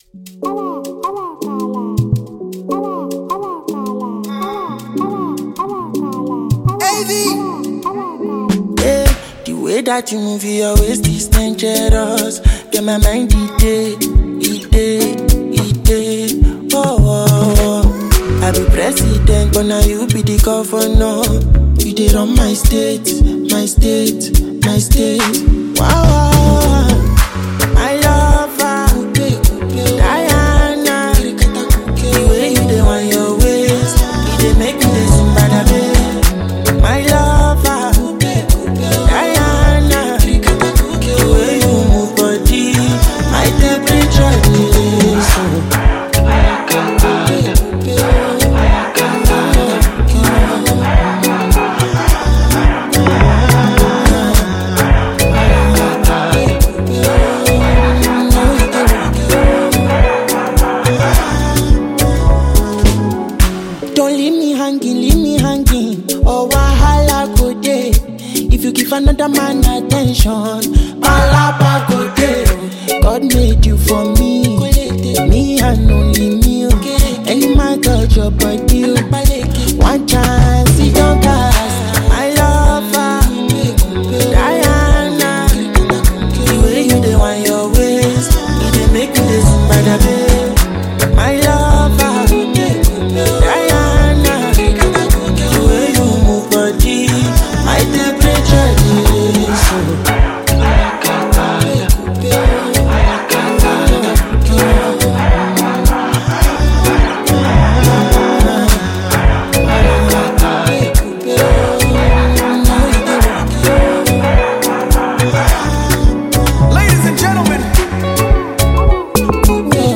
thrilling new gbedu song